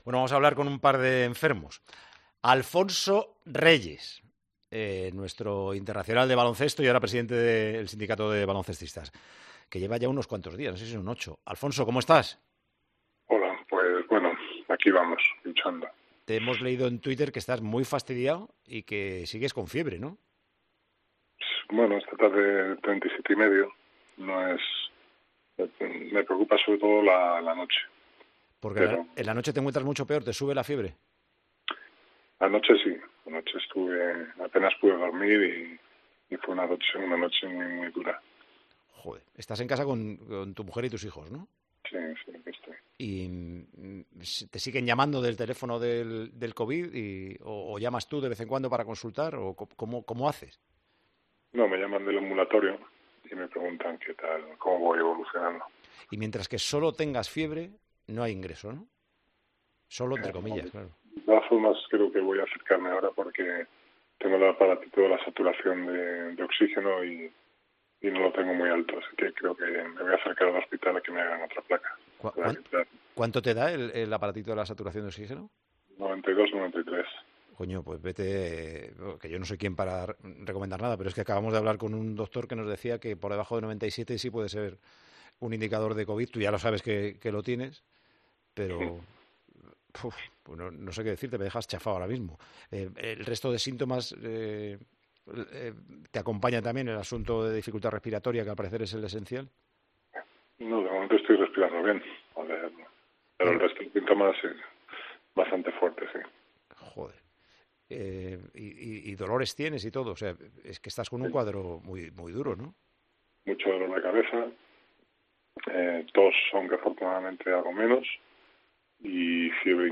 Durante la entrevista con Paco González, su temperatura era de "37 y medio, aunque me preocupa sobre todo la noche. Anoche estuve apenas sin dormir, fue una noche muy dura", declaró.